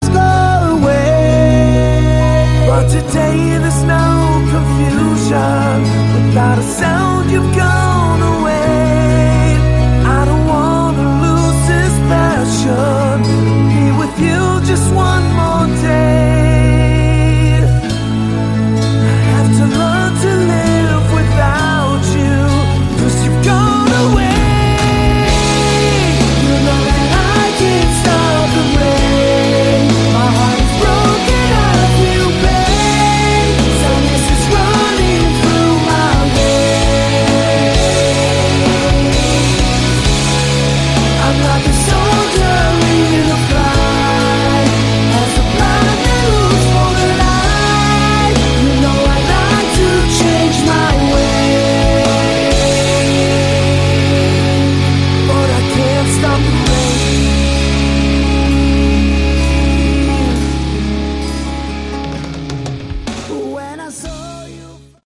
Category: Hard Rock
guitar
keyboards
lead vocals
bass
drums